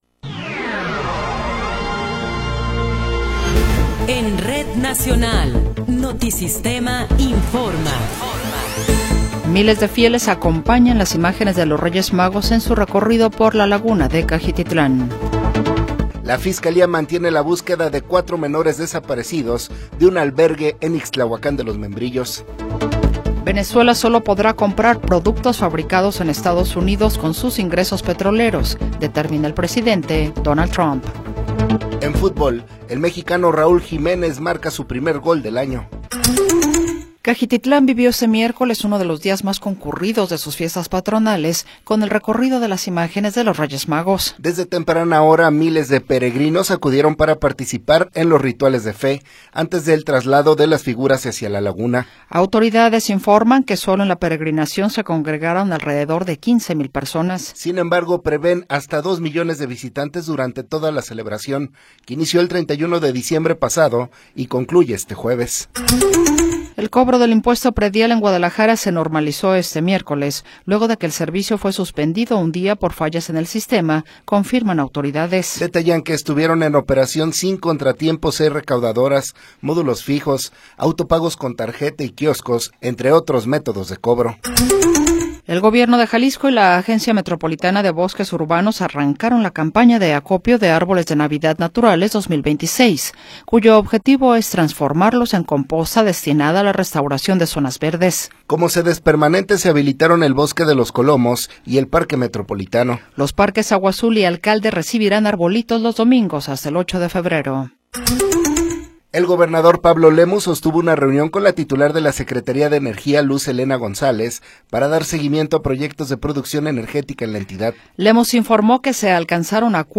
Noticiero 20 hrs. – 7 de Enero de 2026
Resumen informativo Notisistema, la mejor y más completa información cada hora en la hora.